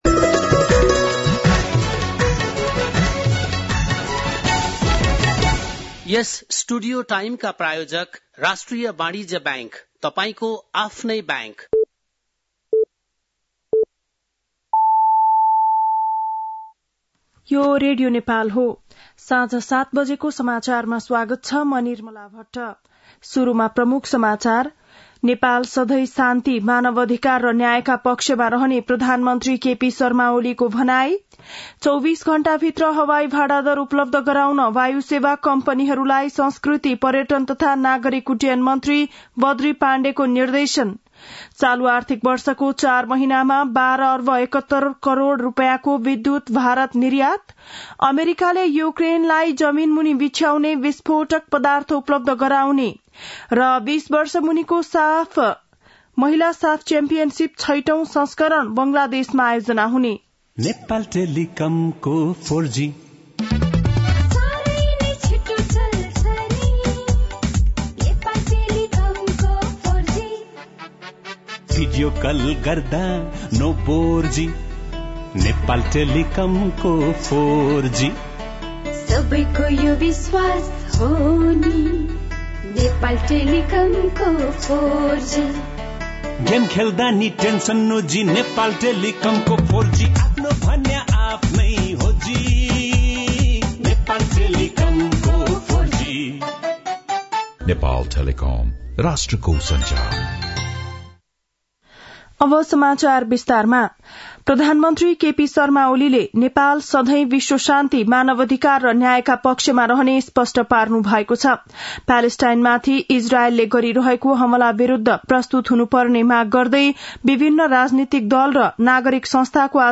बेलुकी ७ बजेको नेपाली समाचार : ६ मंसिर , २०८१
7-PM-Nepali-News-8-5.mp3